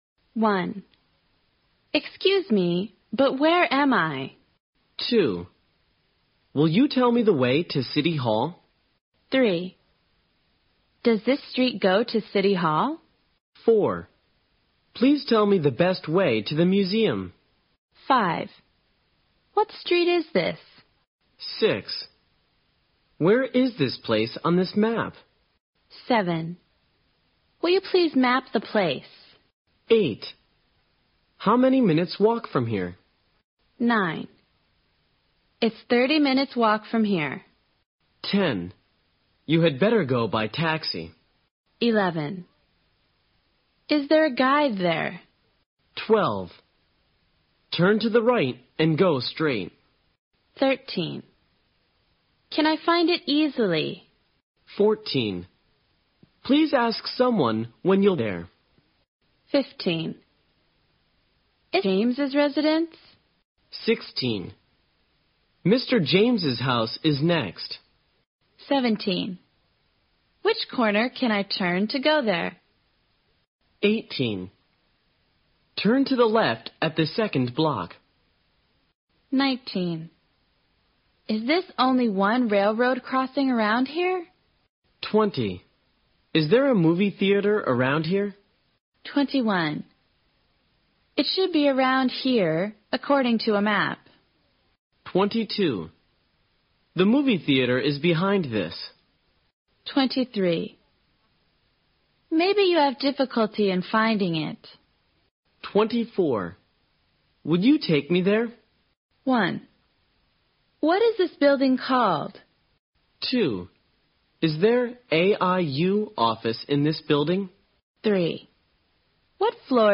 在线英语听力室随身应急英语会话 第13期:在城里的紧急情况(1)的听力文件下载, 《随身应急英语会话》包含中英字幕以及地道的英语发音音频文件，是学习英语口语，练习英语听力，培养提高英语口语对话交际能力的好材料。